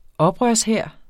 Udtale [ ˈʌbʁɶɐ̯s- ]